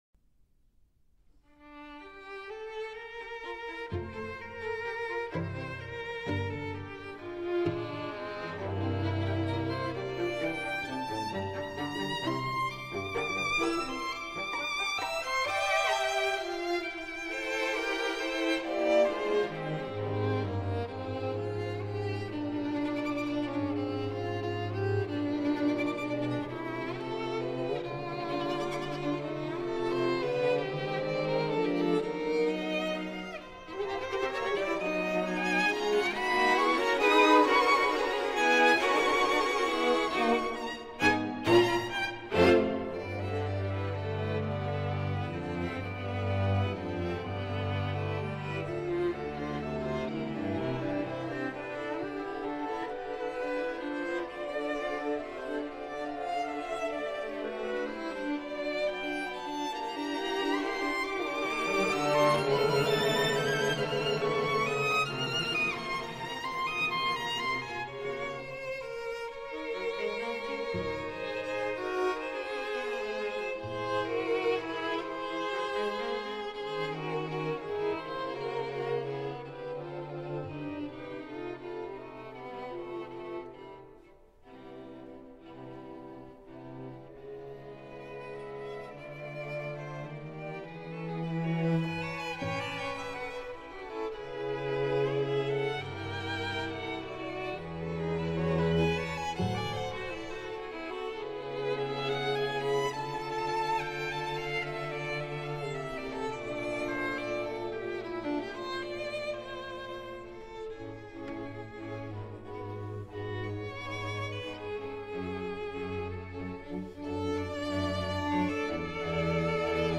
The music is mostly subdued by filled with lyrical themes.